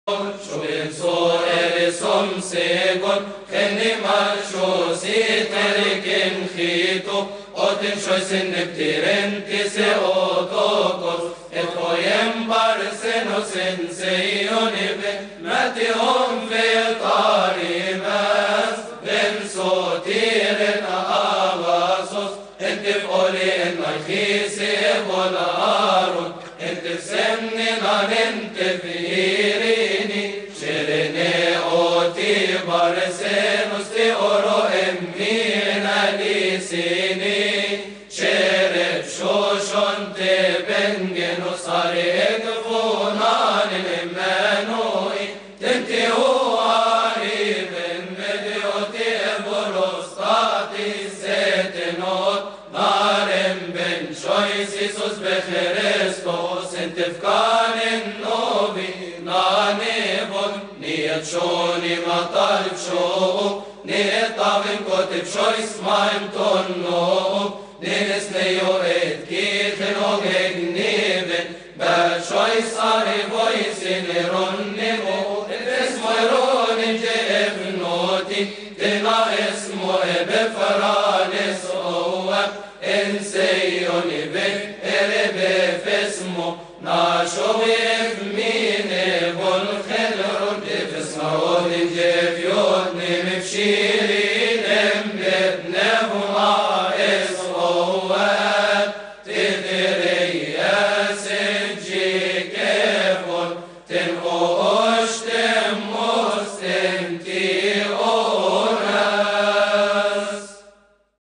ختام-الذوكصولوجيات-باللحن-الكيهكي-خوروس-معهد-الدراسات.mp3